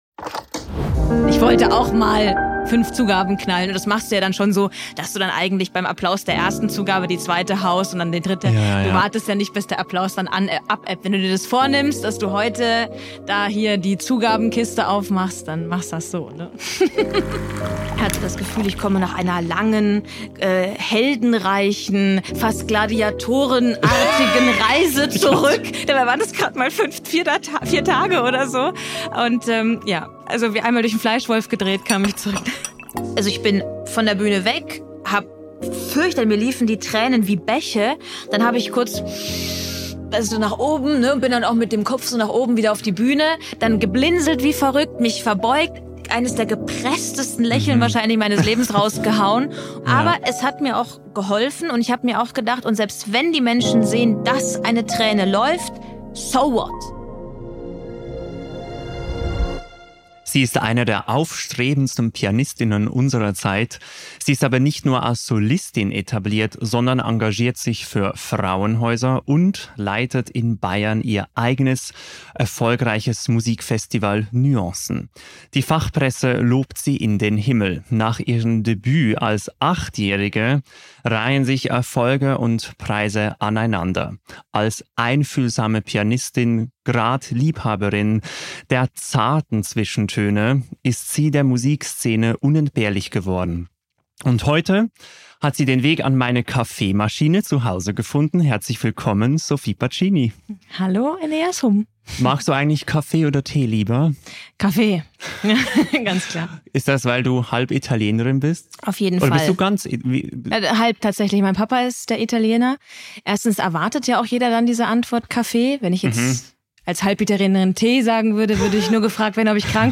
Die Pianistin Sophie Pacini besuchte mich an meiner Kaffeemaschine und wir sprachen über ihre faszinierende Karriere, ihr Engagement für Frauenhäuser sowie ihre wildesten Konzerterfahrungen.